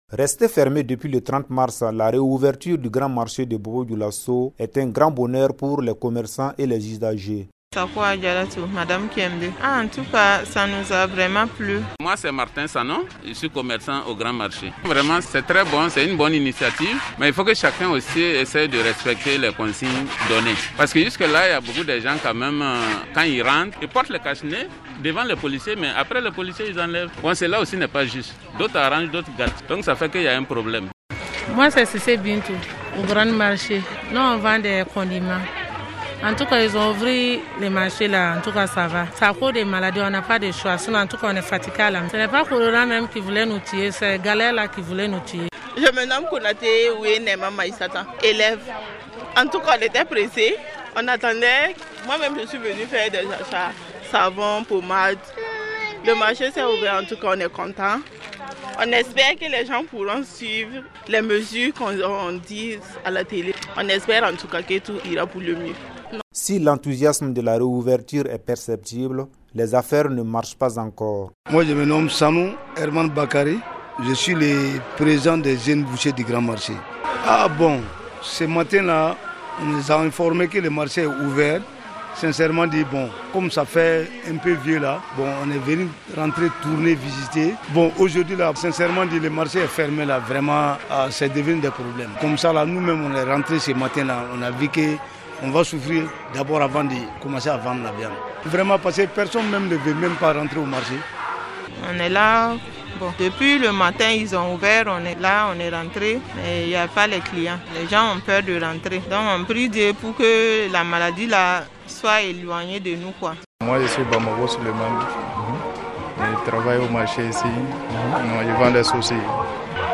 Le grand marché de Bobo-dioulasso a rouvert ses portes ce mardi 21 avril après quelques semaines de fermeture liée au covid-19. Les précautions édictées par les autorités avant l’ouverture sont respectées par la plupart des acteurs mais l’affluence reste timide pour l’instant. Le reportage